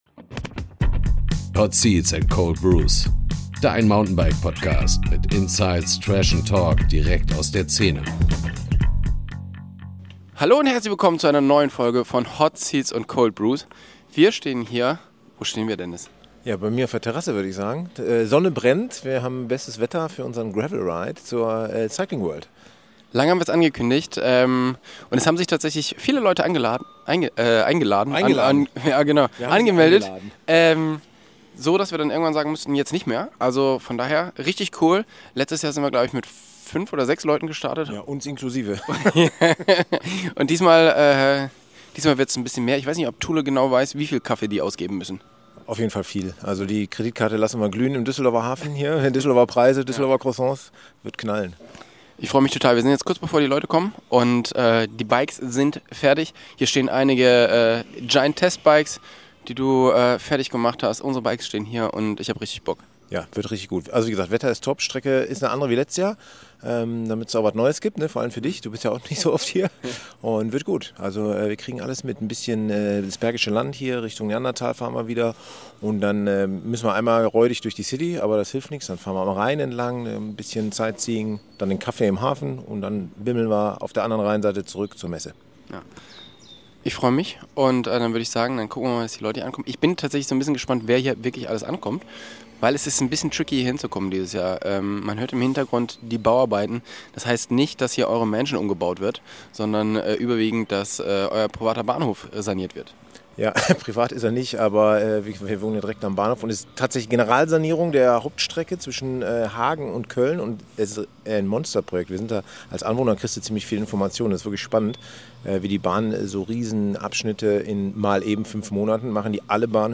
Diese Folge ist anders: Wir nehmen euch live mit auf unseren Community Ride Richtung Cycling World in Düsseldorf. Gemeinsam mit euch im Sattel entstehen echte Gespräche, spontane Momente und genau die Geschichten, die unseren Podcast ausmachen.
Zwischen Gravel, Kaffee-Stop und Ziel am Areal Böhler verschmelzen Ride und Podcast – direkt, ungefiltert und mittendrin.